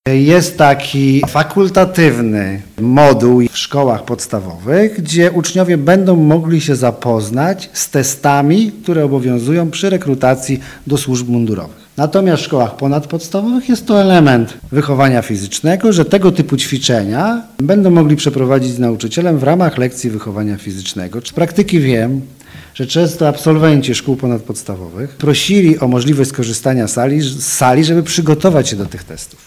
Jak wyjaśnia Paweł Palczyński, zachodniopomorski kurator oświaty: